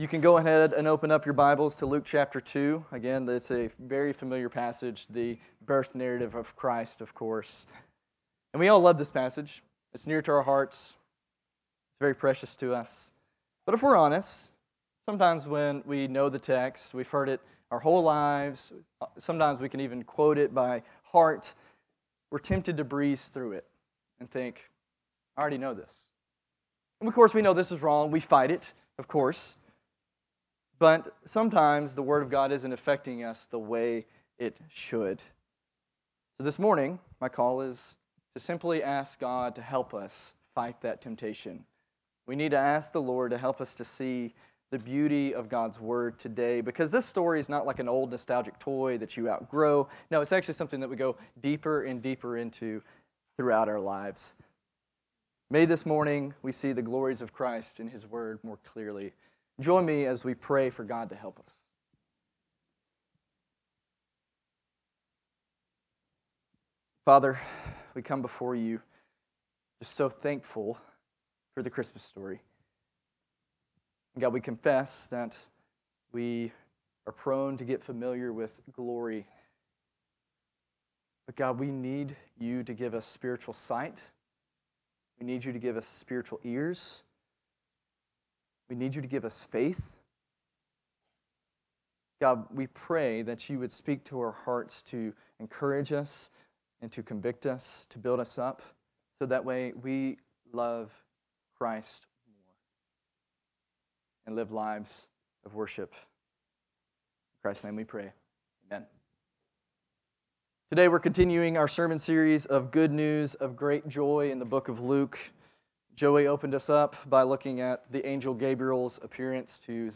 Good News of Great Joy Passage: Luke 2:1-20 Service Type: Sunday Morning Topics